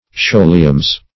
scholiums.mp3